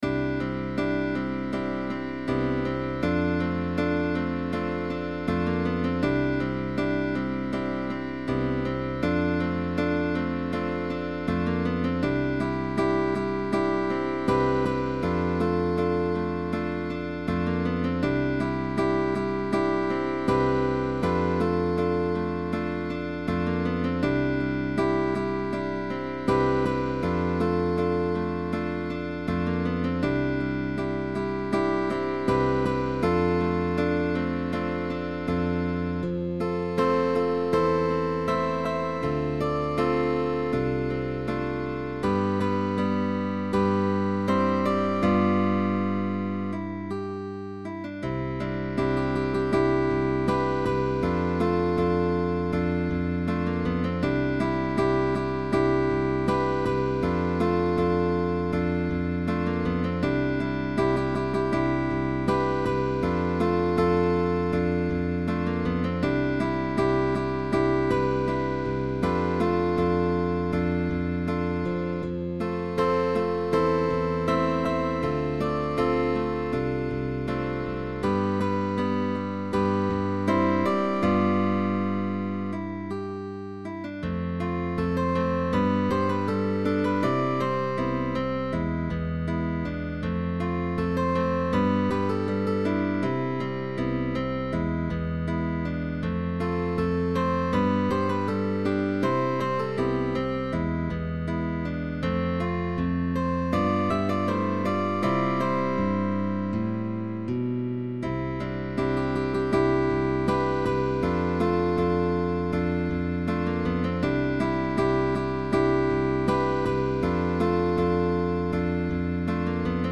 GUITAR QUARTET